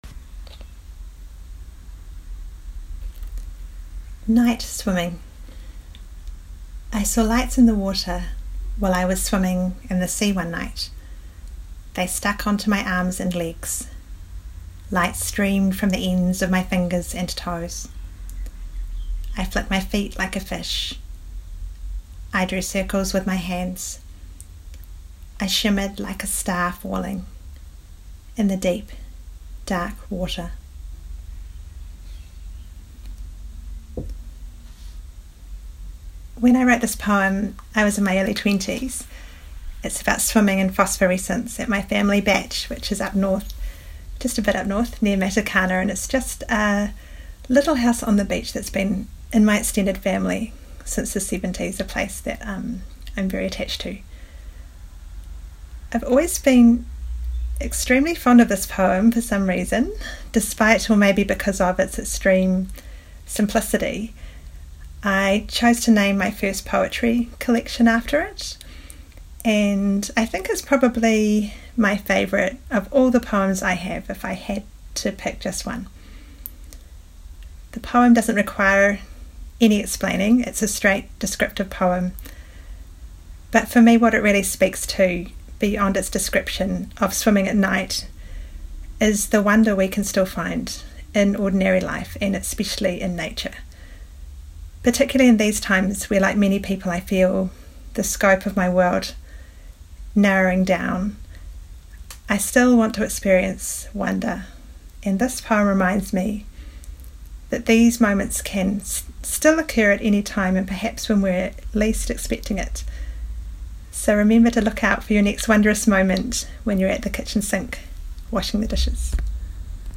reads and discusses her poem, ‘NIght Swimming’